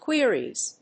/ˈkwɪriz(米国英語), ˈkwɪri:z(英国英語)/